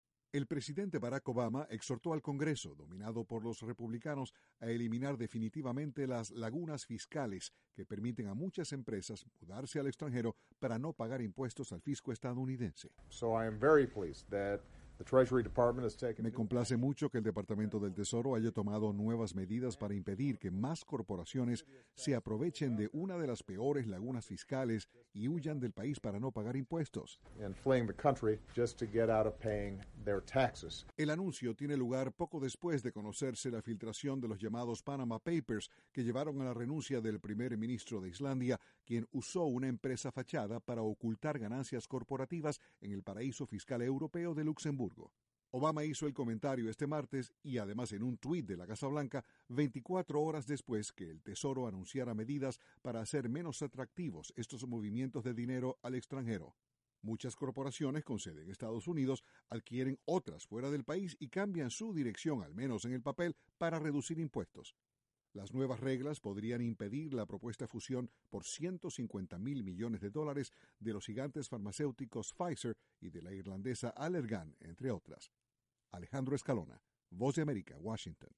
El presidente de Estados Unidos, Barack Obama, instó al Congreso a eliminar definitivamente las “lagunas fiscales”. Desde la Voz de América, Washington